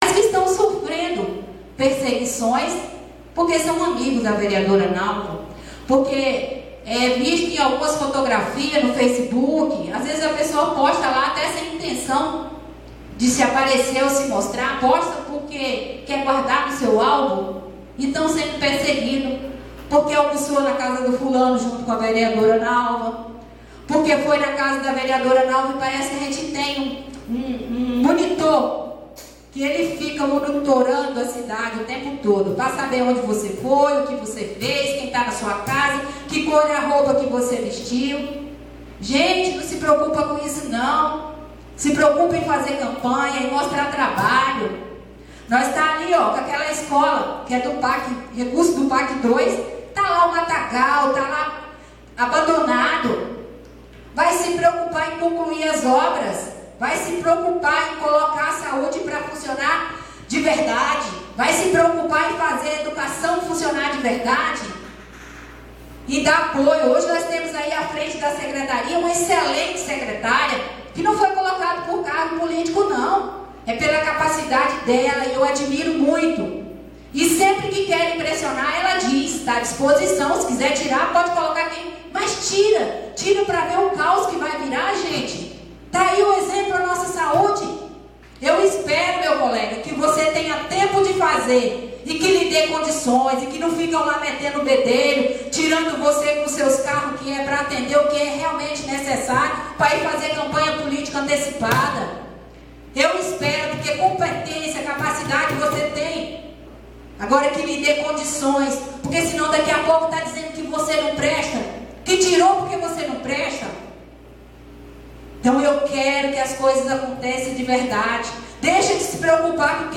Veja o resumo da sessão nos áudios abaixo: Pronunciamentos da Vereadora Nalva…
…e do vereador Valter Siqueira, com apartes.